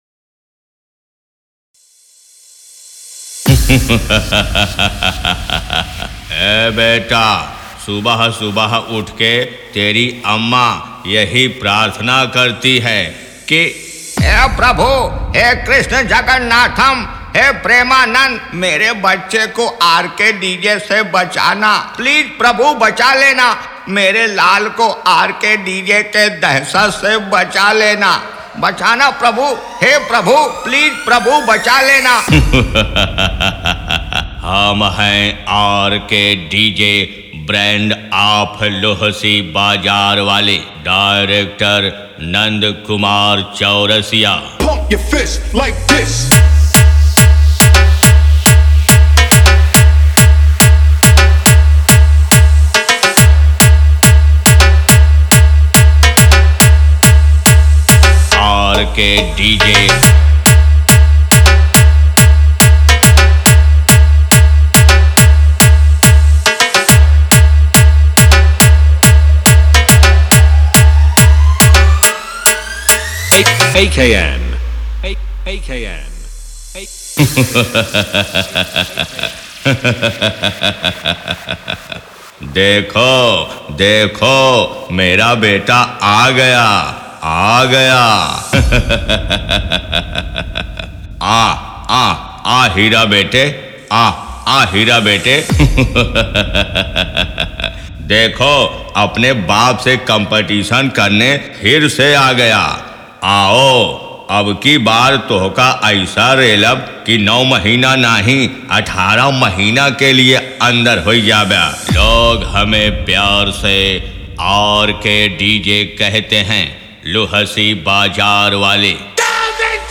Competition DJ Song
Ultra Bass DJ Remix
Bass Boosted DJ Song, Devotional DJ Remix